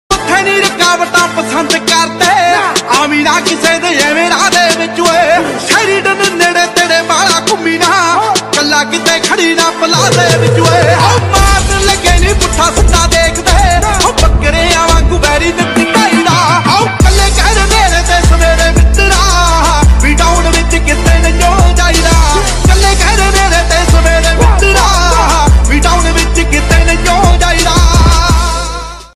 Ringtones Category: Bollywood